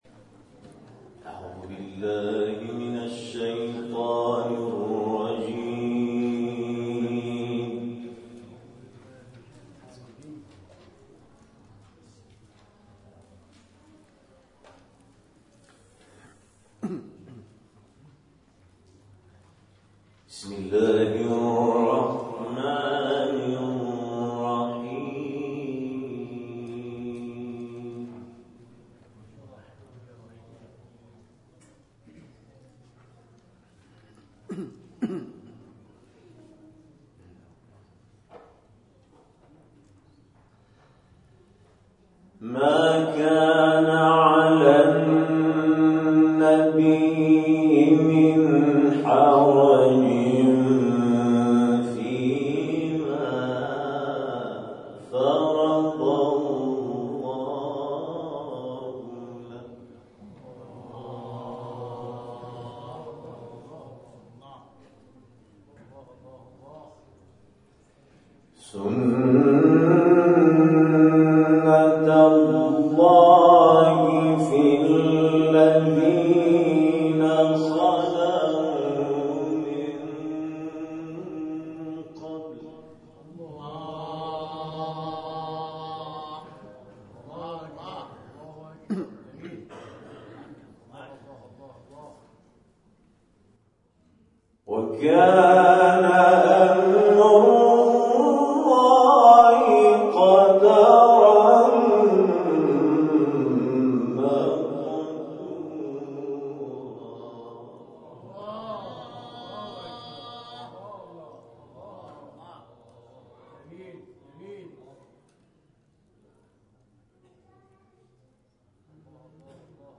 محفل انس با قرآن کریم در آستان امامزادگان پنج تن لویزان